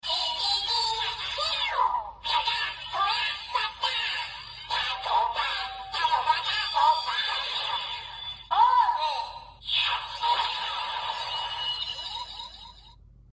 OOO饱藏音效.MP3